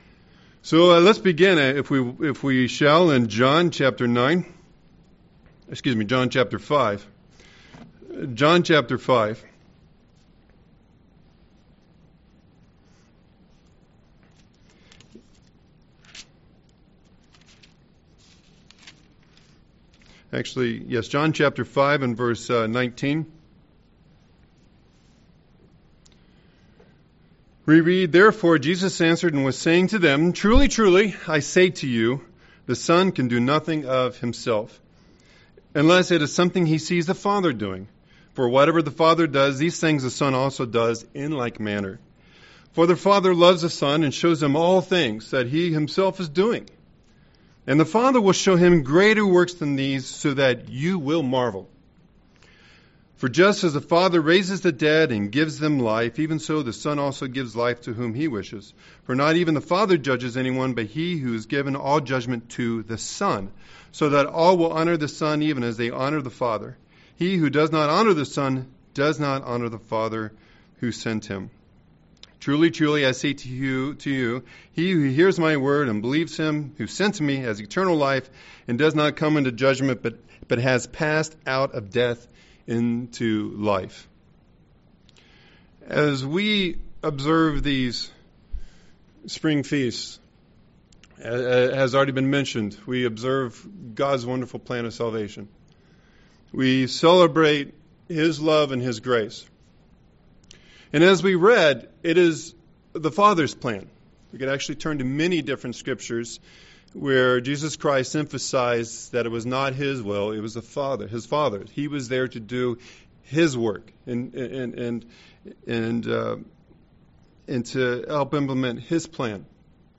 Given in Milwaukee, WI
UCG Sermon Miracles Studying the bible?